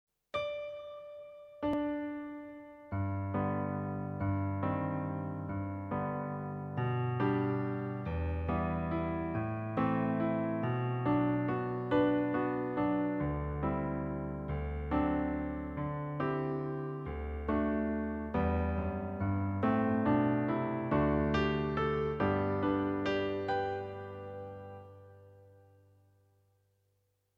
begeleiding